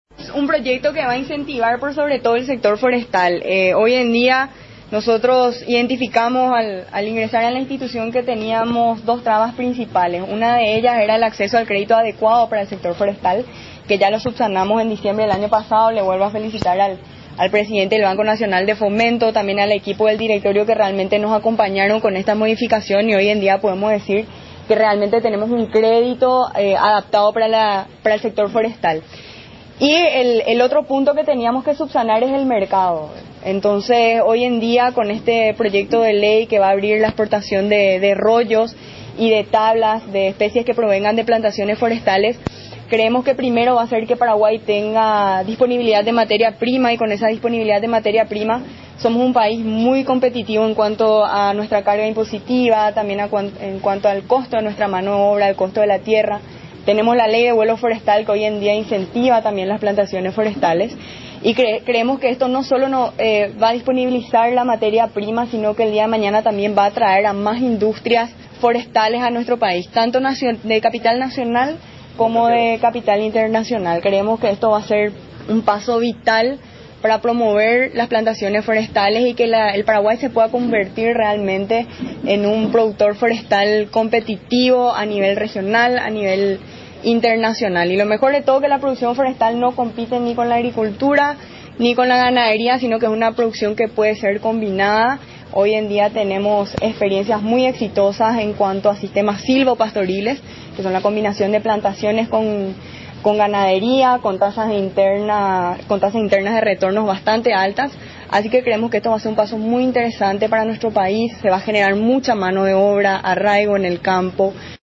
Brindó más detalles acerca de esto la presidente del INFONA, Cristina Goralewski.